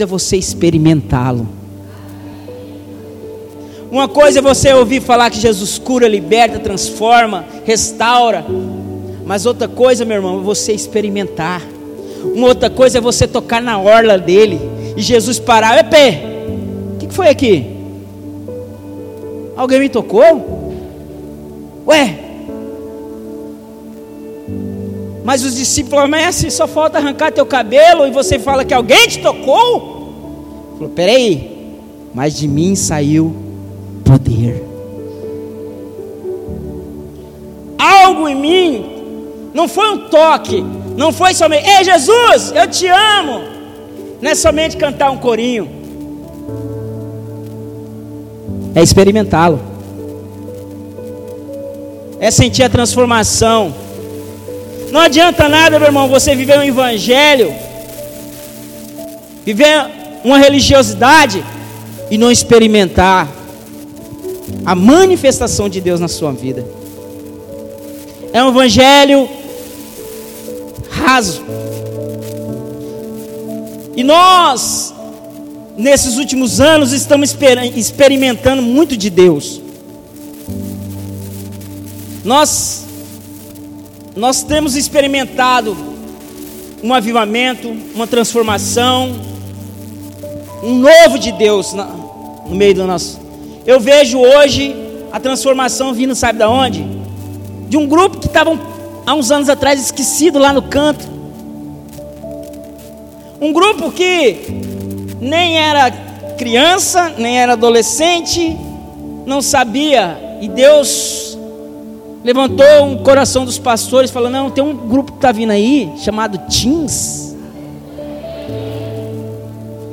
Em Culto de Celebração